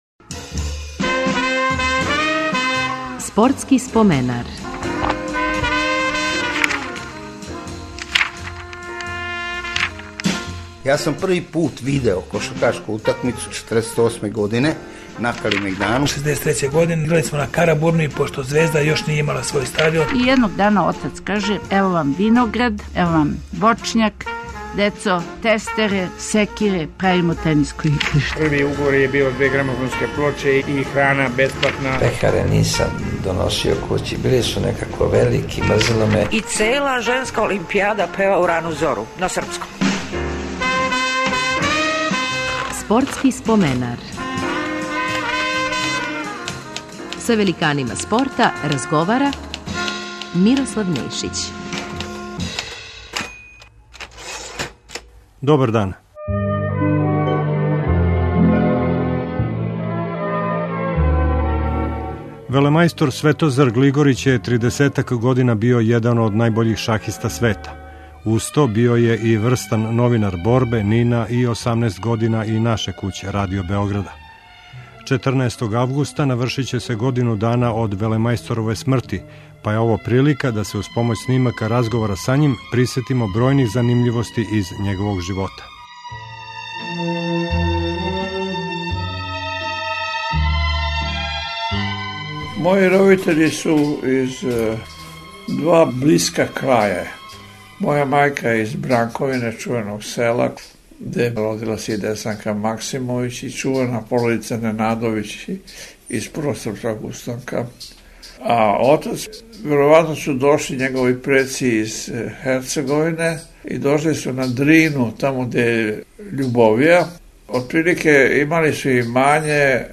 Ове недеље навршава се година дана од смрти легендарног шахисте Светозара Глигорића. У Споменару ћете имати прилику да чујете инсерте из његових бројних гостовања на таласима Радио Београда током неколико деценија.